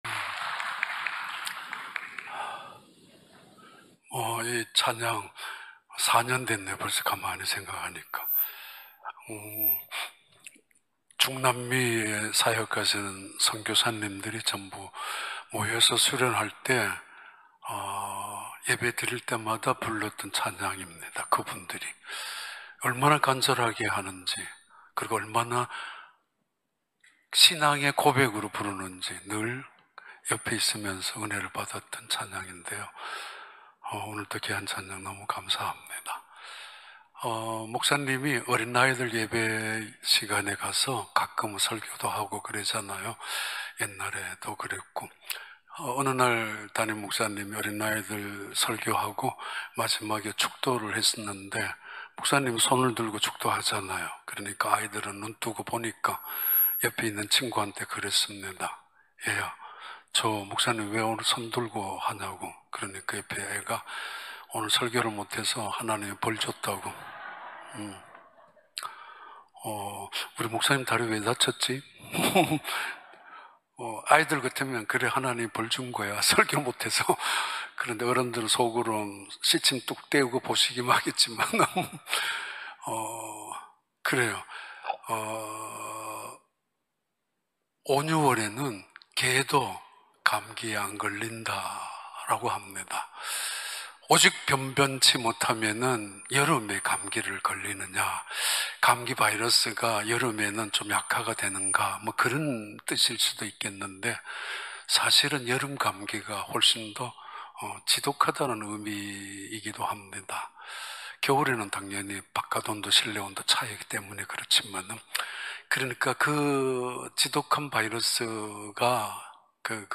2021년 8월 8일 주일 3부 예배